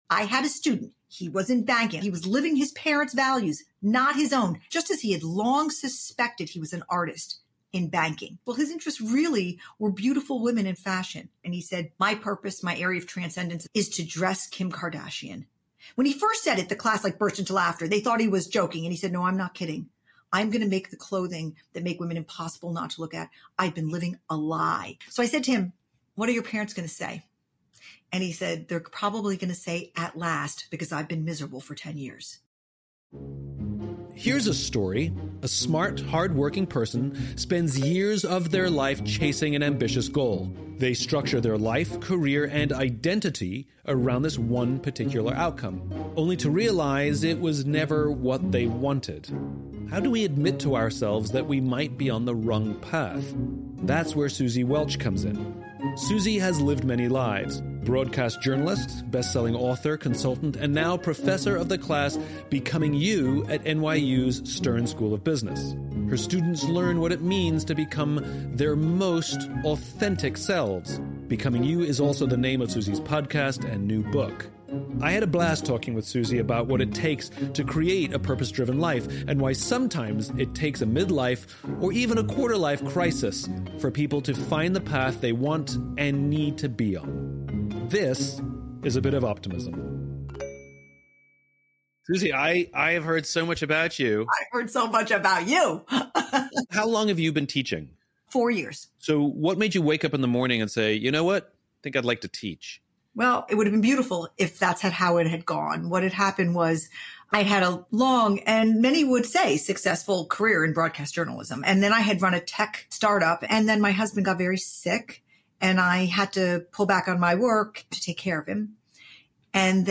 In this conversation, she shares with me the difference between passion and aptitude, the reason luck is overrated, and why so many people struggle to know their own values.